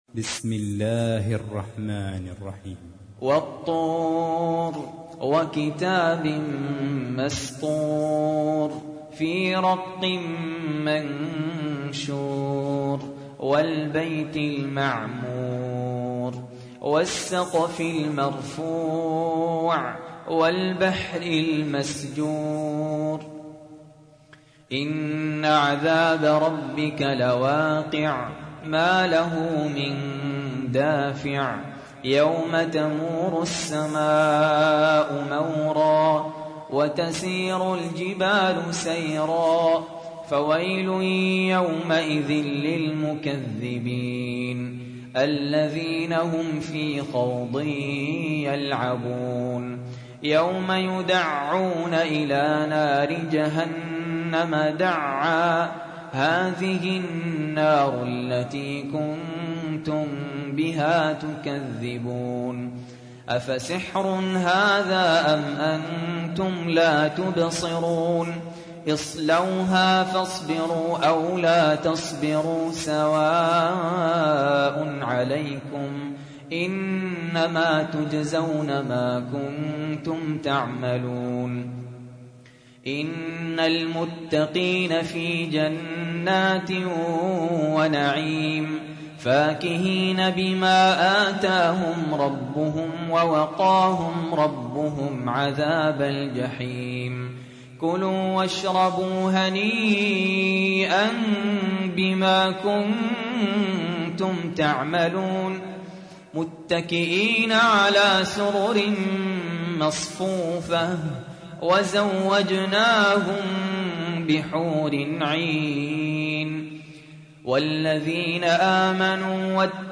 52. سورة الطور / القارئ